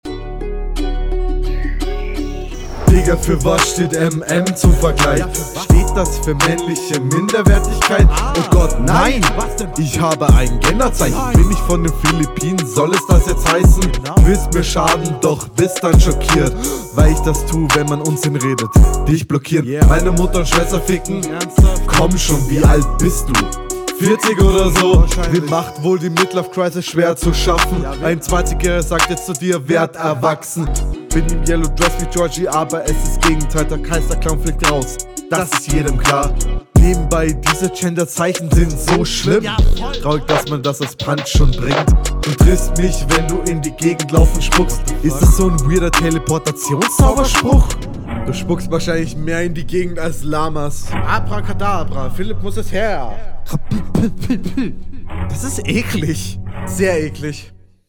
du bist schon mal lauter als dein gegner was ich mag. flow is bei euch …